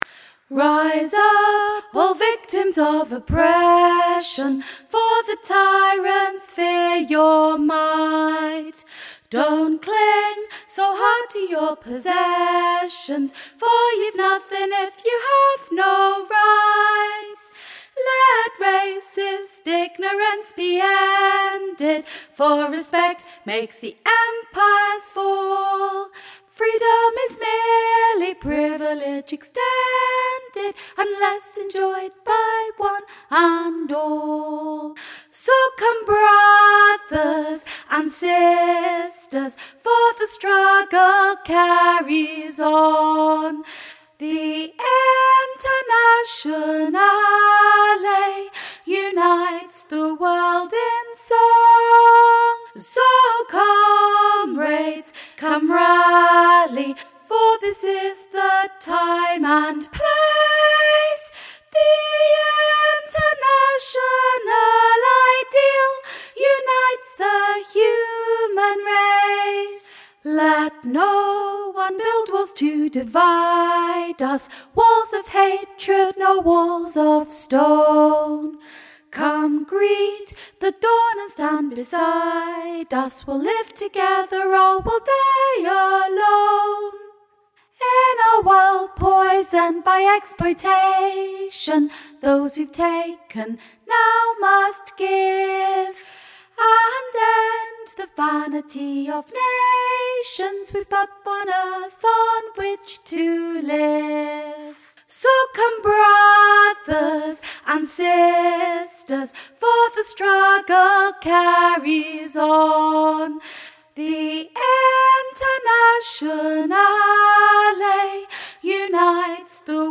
internationale_soprano
internationale_soprano.mp3